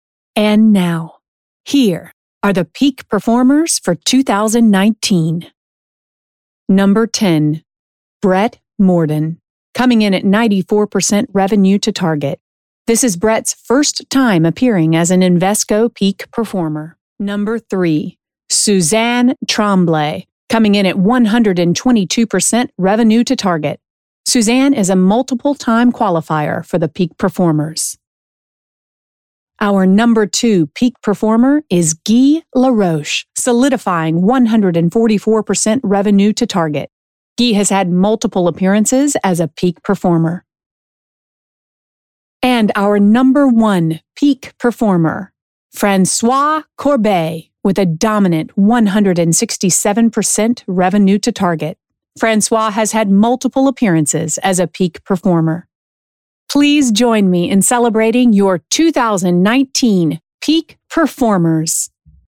Live Announce - Female Voice of God (VOG)
Pre-recorded or live, in-person voiceover talent for your event.
Sales Conference